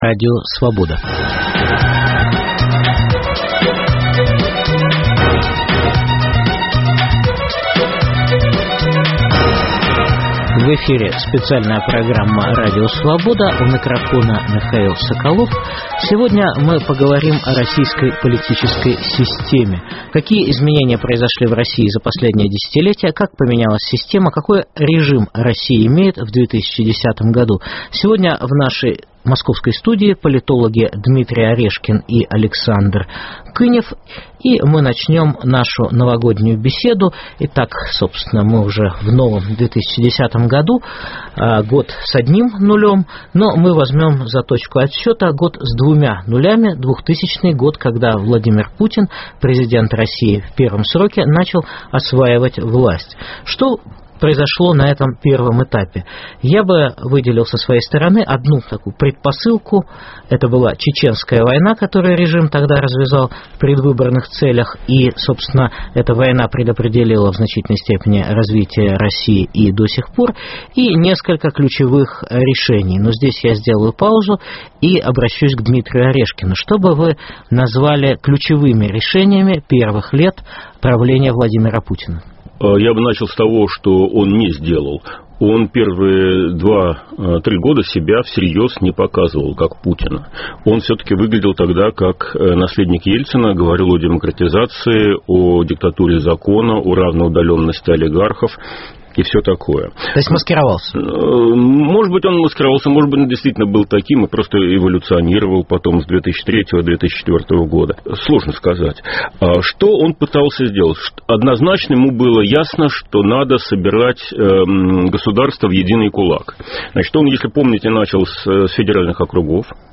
Политические процессы последнего десятилетия обсуждают эксперты